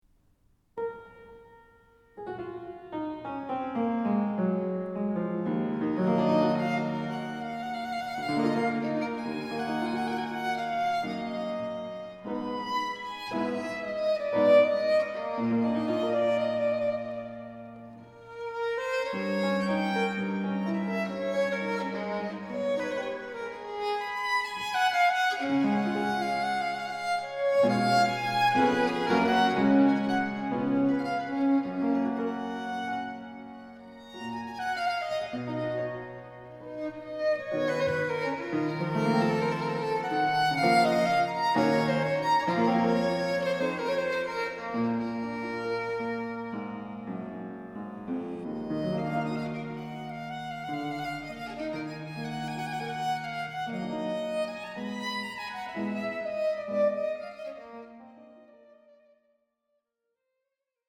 E minor, for violin and basso continuo)1:01